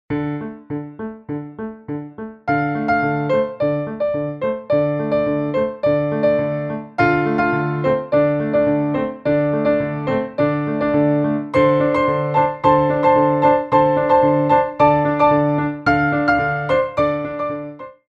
4/4 (8x8)